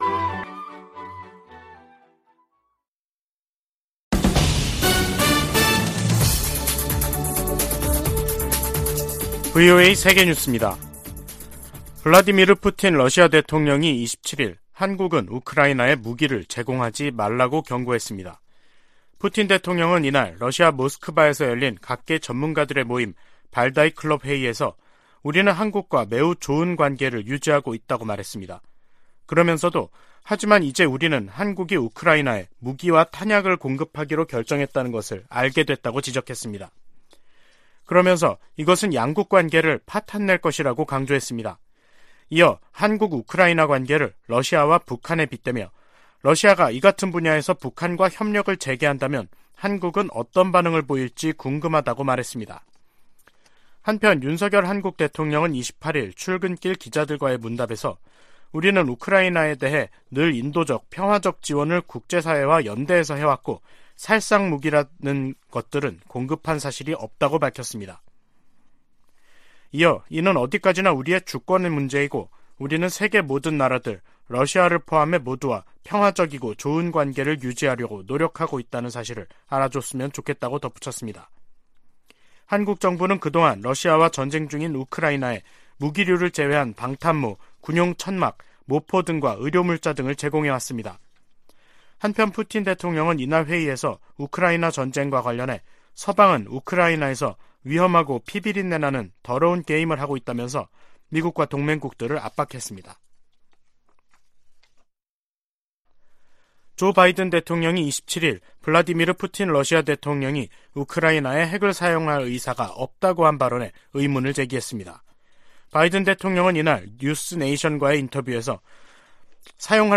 VOA 한국어 간판 뉴스 프로그램 '뉴스 투데이', 2022년 10월 28일 3부 방송입니다. 북한이 28일 동해상으로 단거리 탄도미사일(SRBM) 두 발을 발사했습니다. 북한이 7차 핵실험에 나선다면 국제사회가 엄중한 대응을 할 것이라고 백악관 고위관리가 밝혔습니다. 미 국방부는 북한 정권이 핵무기를 사용하고 살아남을 수 있는 시나리오는 없다고 경고했습니다.